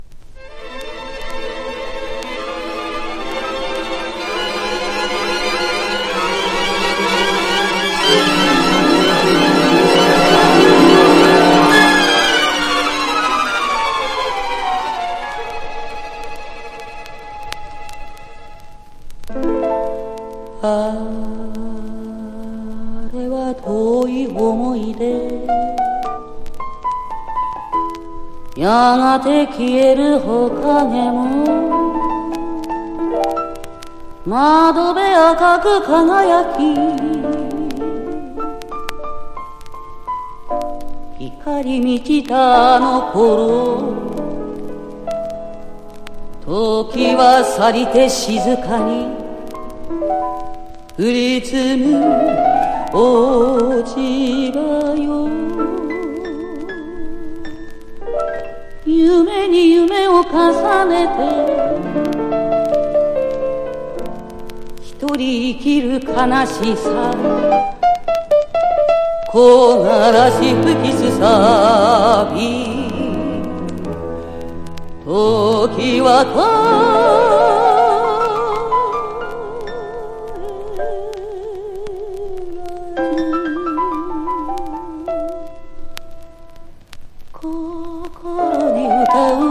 和モノ / ポピュラー